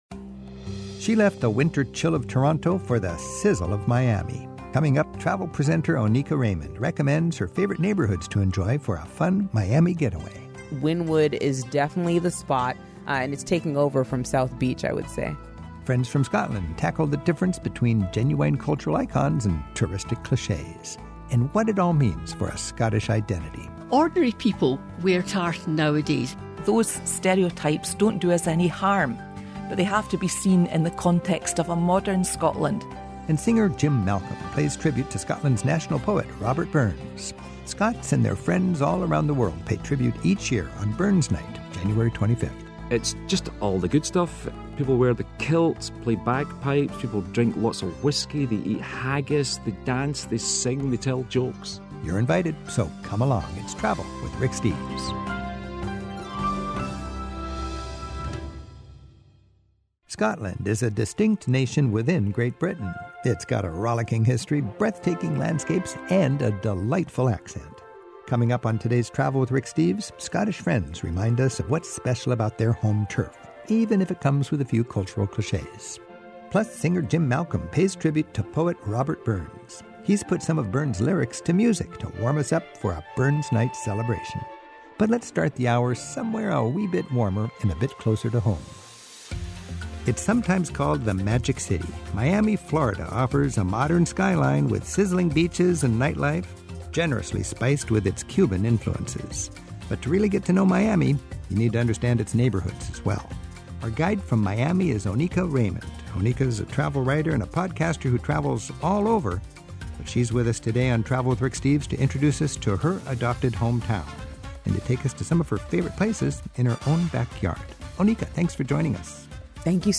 My Sentiment & Notes 780 Miami Getaway; Scottish Icons; Robert Burns Night Podcast: Travel with Rick Steves Published On: Sat Jan 18 2025 Description: Travel Channel host Oneika Raymond recommends her favorite Miami neighborhoods to explore on a sunny getaway. Then friends from Scotland discuss the uniquely Scottish cultural icons that reinforce the small nation's strong sense of identity.